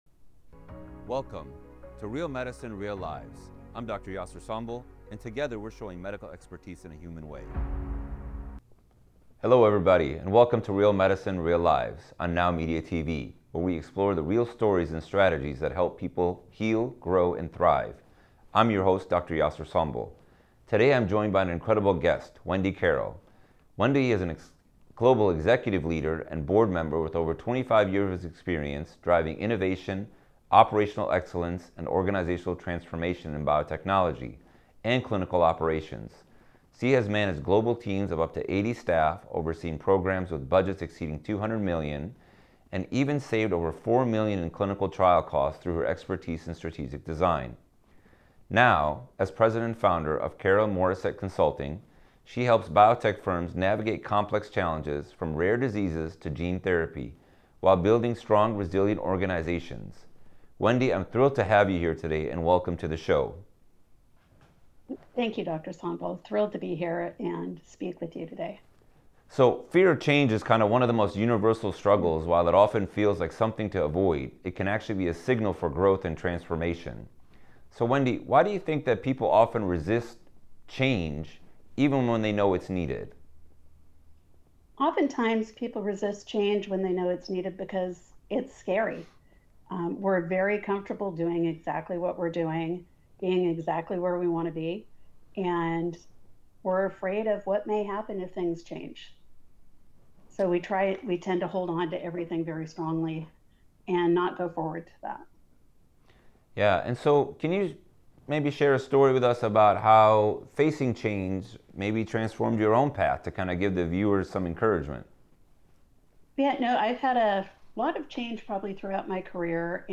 Discover practical strategies to balance results with relationships, lead with compassion instead of control, and protect work-life harmony in a 24/7 connected world. From setting boundaries to empowering teams through trust and empathy, this conversation offers real-world lessons for professionals at every level.